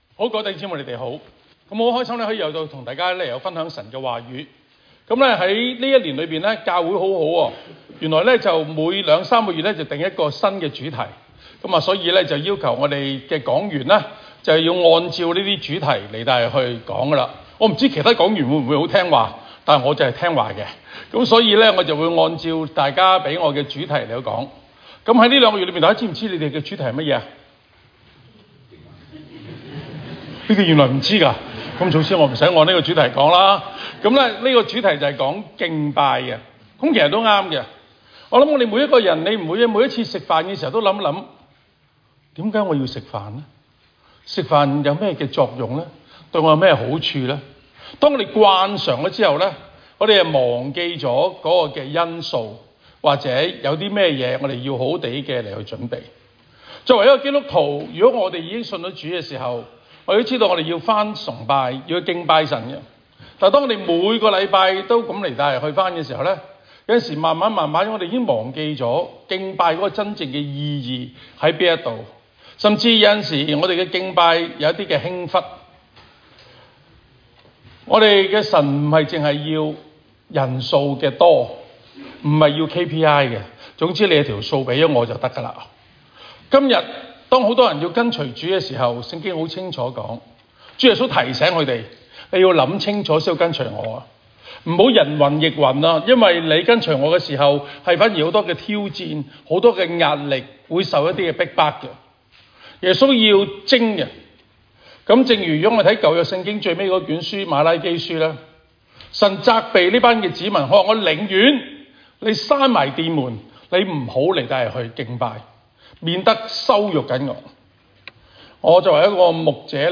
場所：加利略山華人基督教會主日崇拜